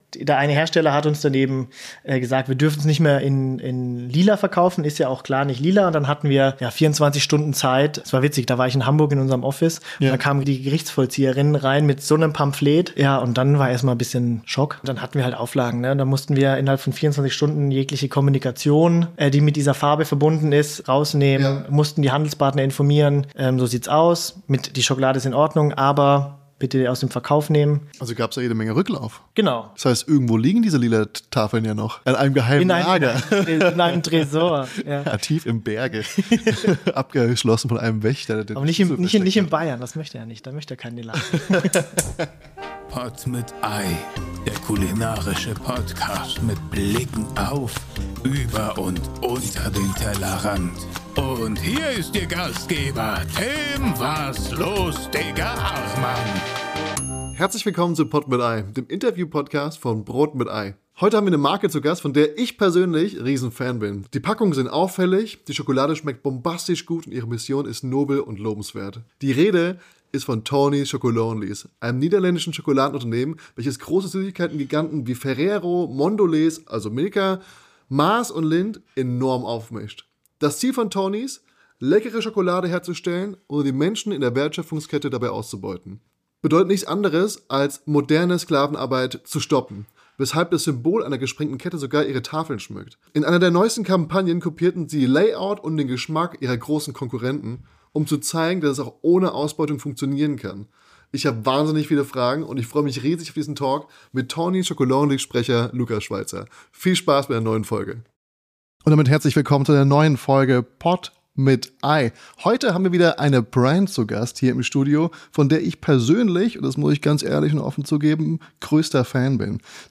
Herzlich Willkommen zu Pod mit Ei, dem Interview Podcast von Brot mit Ei!